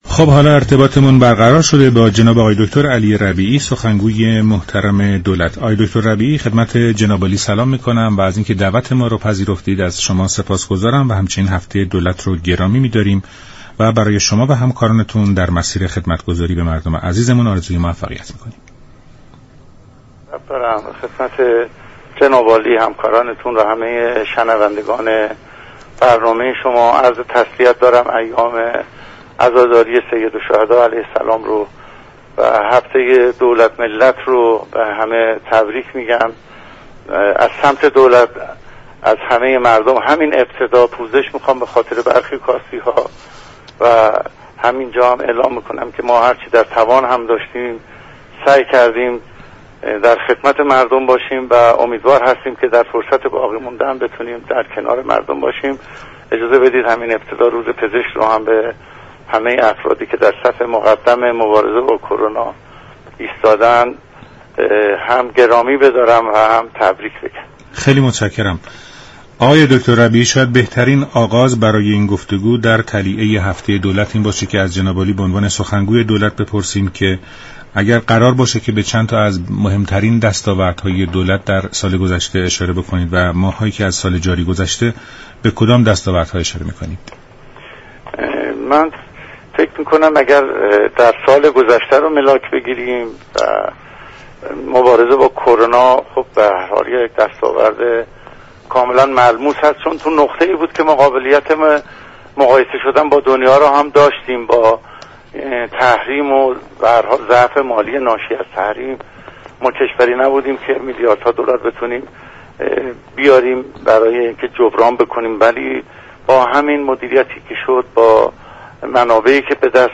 به گزارش شبكه رادیویی ایران، «علی ربیعی» سخنگوی دولت در برنامه «ایران امروز» ضمن تبریك هفته دولت به دستاوردهای دولت پرداخت و گفت: در ماه های اخیر با وجود شرایط مالی كشور و اعمال تحریم ها، ایران با استفاده از منابع موجود خود و جانفشانی های نظام سلامت ایران به جنگ و مبارزه با ویروس كرونا شتافت.